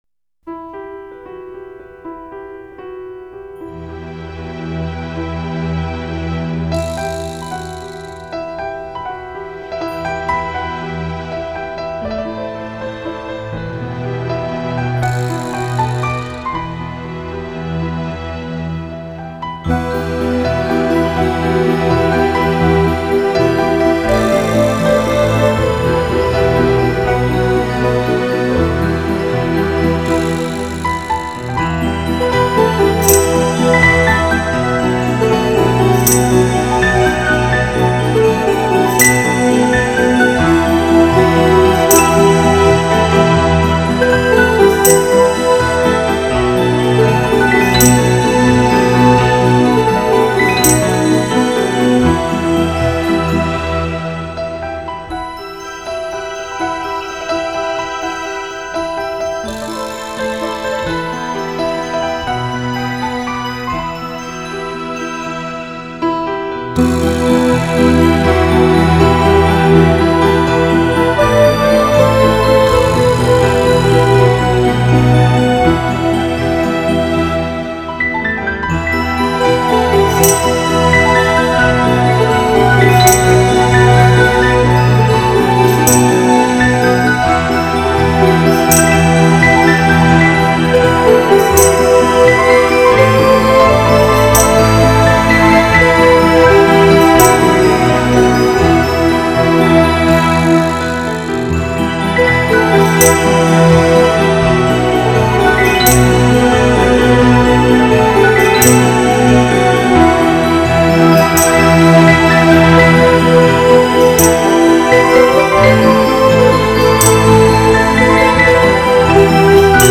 эта музыка играет на протяжении всего коротенького клипа ... фортепиано ...